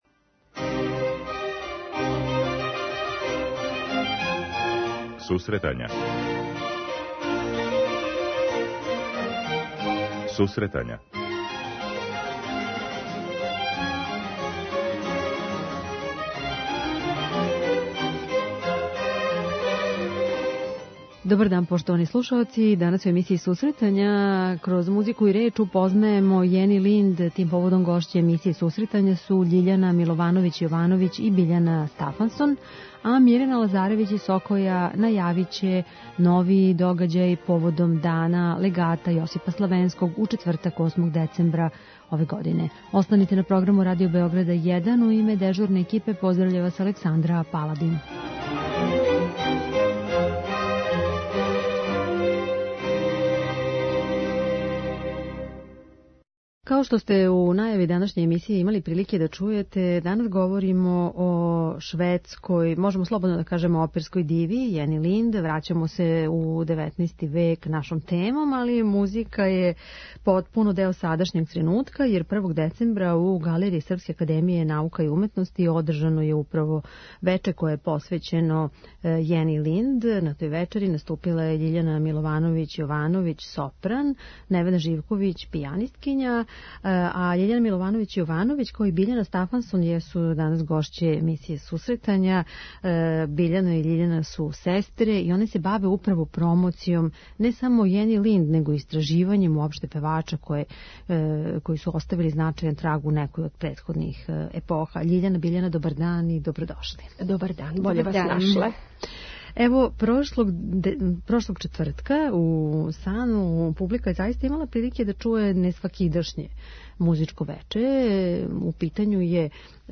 Концерт који је публику подсетио на ту чувену уметницу одржан је 1. децембра, у Галерији Српске академије наука и уметности. преузми : 10.17 MB Сусретања Autor: Музичка редакција Емисија за оне који воле уметничку музику.